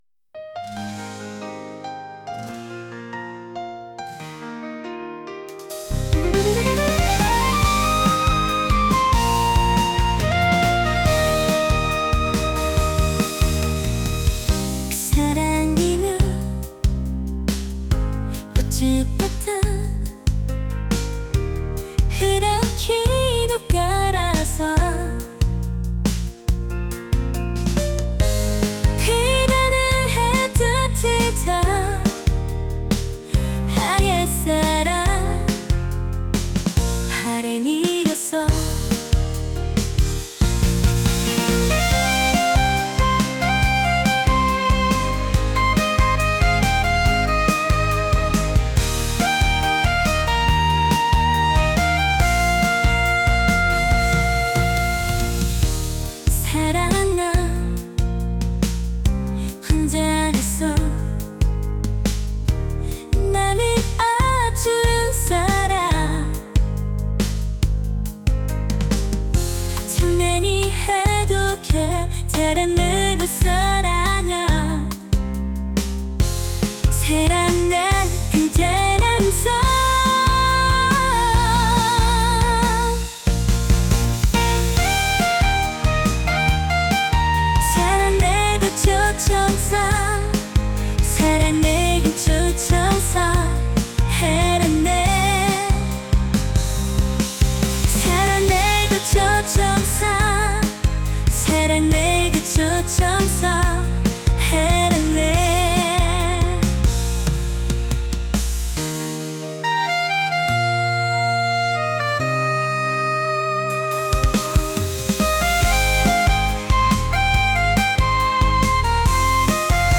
pop | energetic